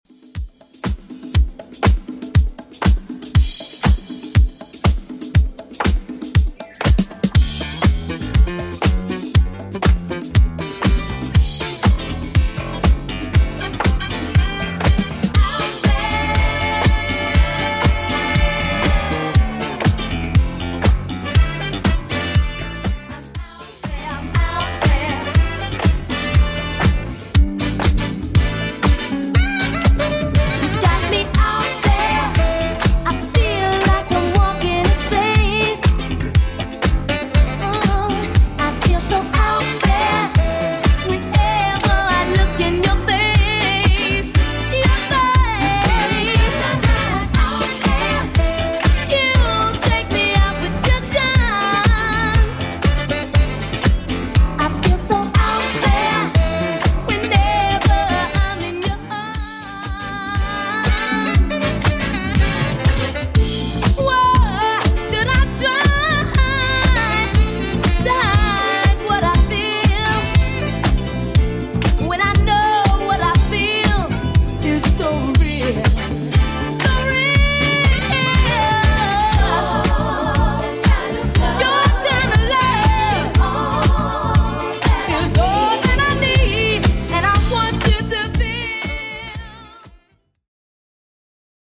Genre: 80's Version: Clean BPM: 105